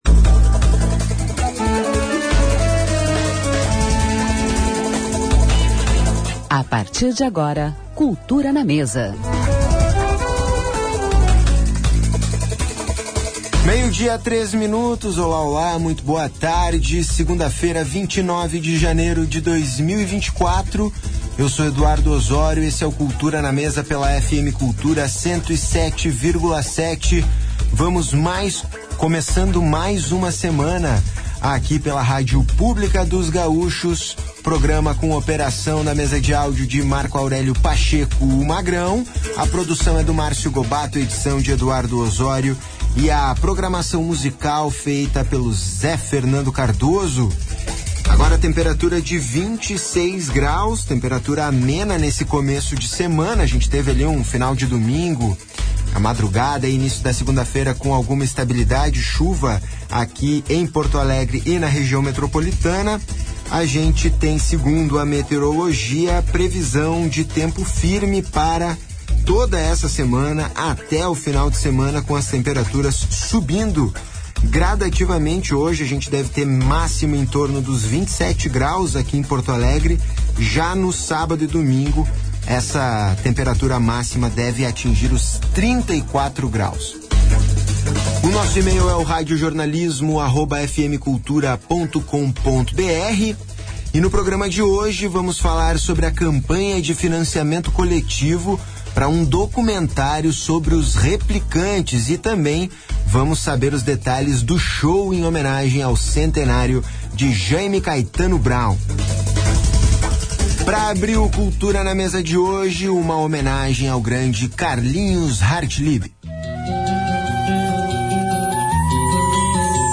Entrevistas
com música ao vivo